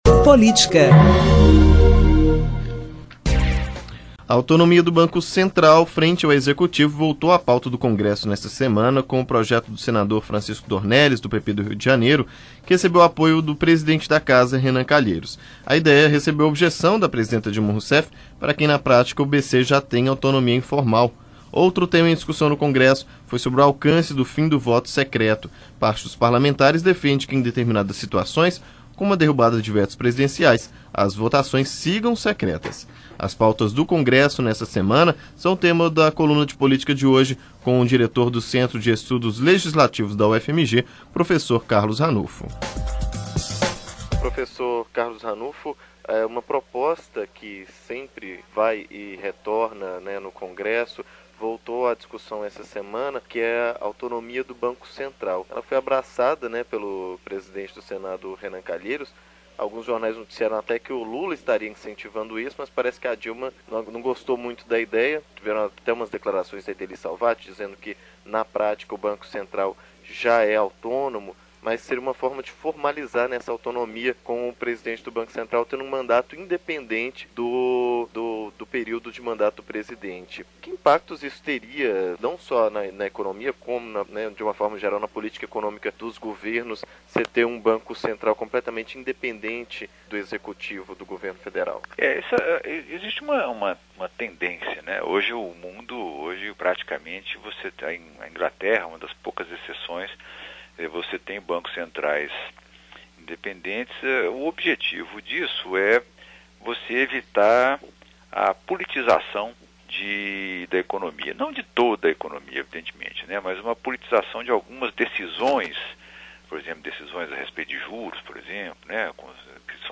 Especialista comenta os temas da pauta do Congresso.